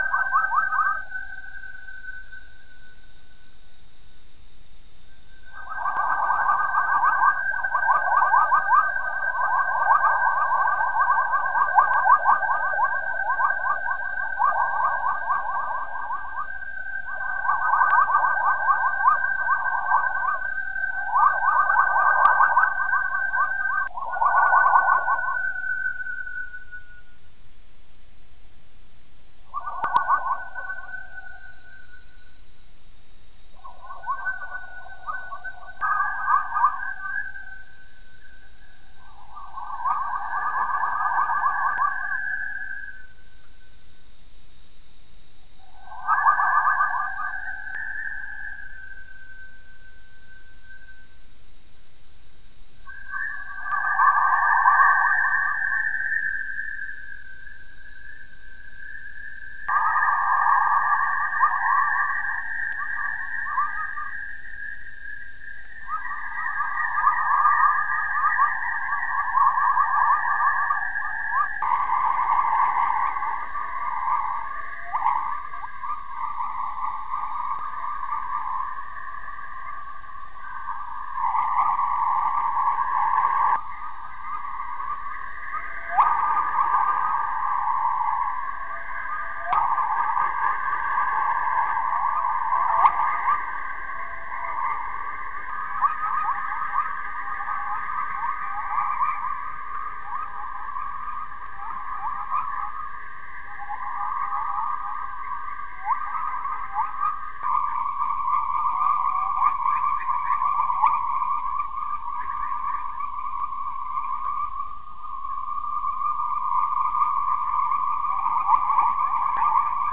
For Halloween we present some spooky chorus recorded by the Van Allen Probes A spacecraft in Earth orbit. These waves may contribute to the acceleration of electrons in Earth's radiation belts up to speeds that allow them to penetrate and damage spacecraft -- the notorious "killer electrons"! This particular example has some ghostly sounding features.
The measurements of three orthogonal magnetic antennas Bu, Bv, and Bw were combined to make the 2-minute stereo audio recording.